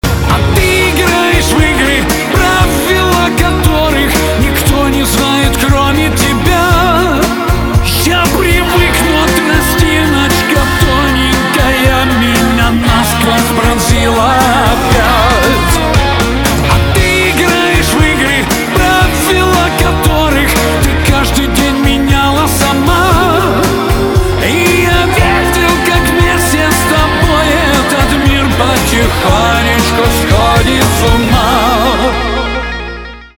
эстрада
гитара , барабаны , чувственные